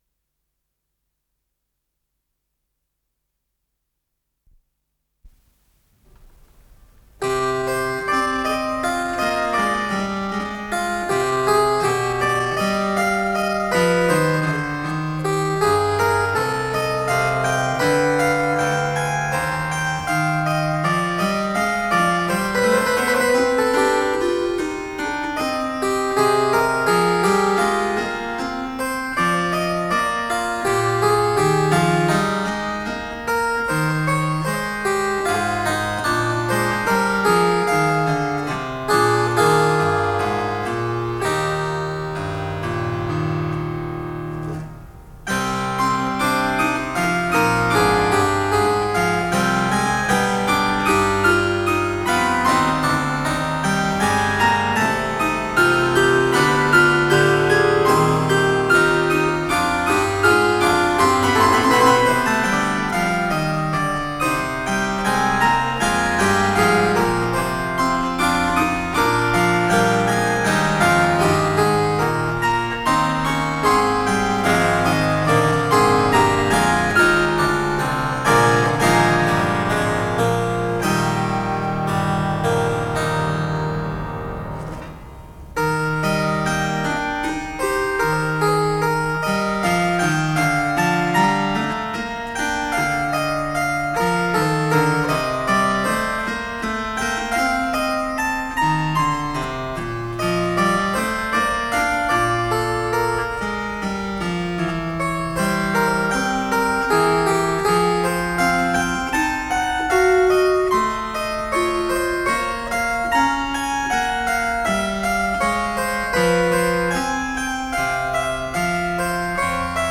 с профессиональной магнитной ленты
ИсполнителиРальф Киркпатрик - клавесин
ВариантДубль моно